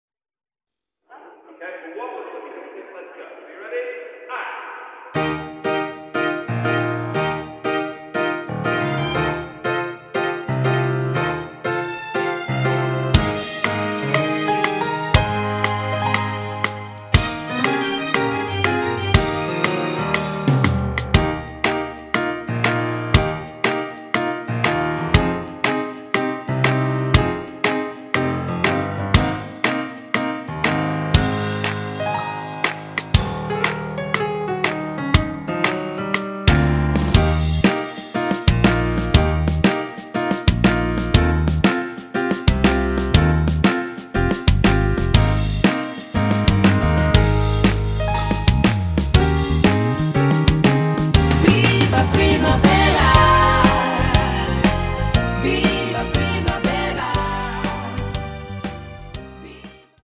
키 Eb 가수